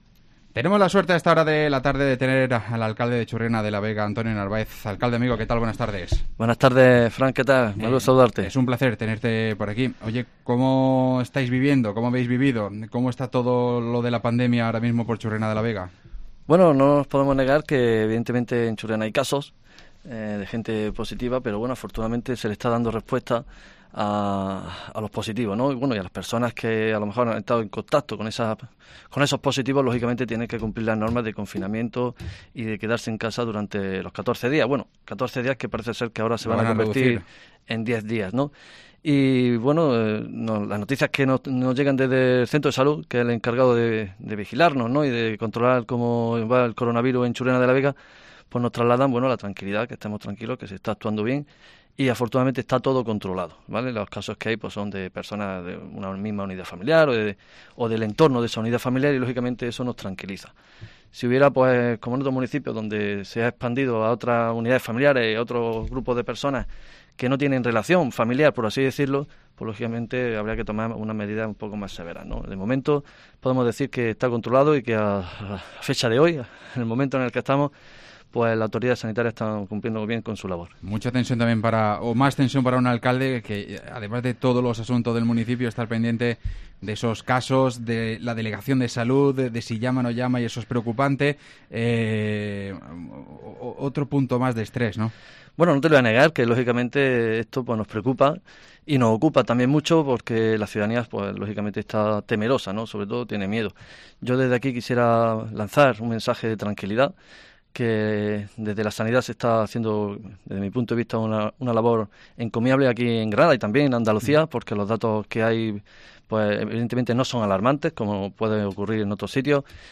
Entrevista a Antonio Narváez, alcalde de Churriana de la Vega